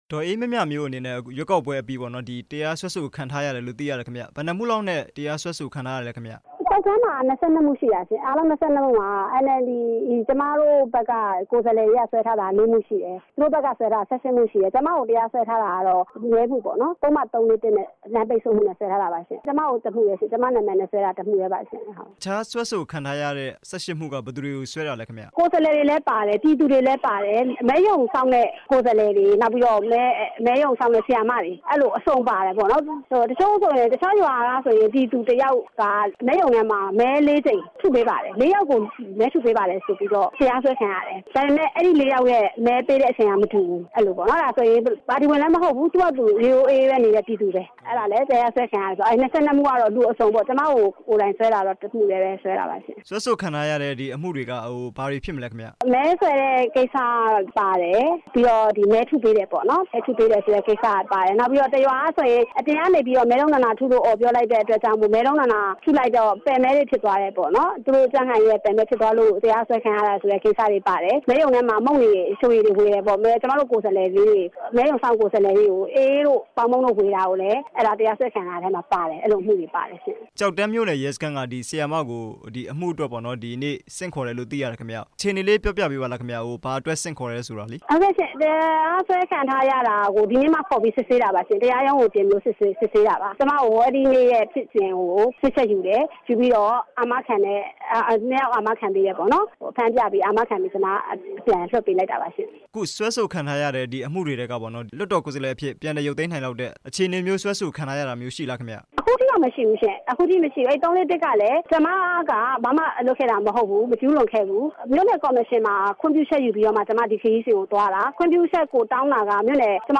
NLD ပြည်သူ့လွှတ်တော်ကိုယ်စားလှယ် ဒေါ်အေးမြမြမျိုးနဲ့ မေးမြန်းချက်